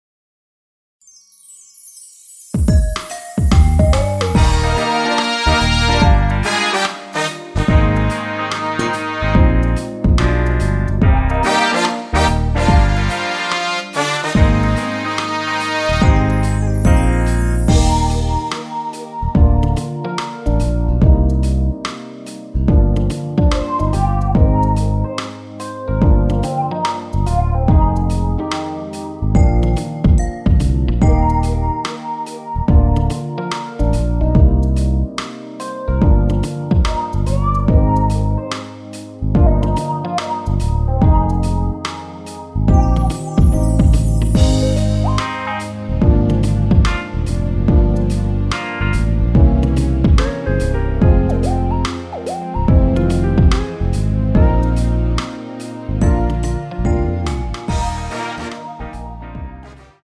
원키에서(-2)내린 MR입니다.
Eb
앞부분30초, 뒷부분30초씩 편집해서 올려 드리고 있습니다.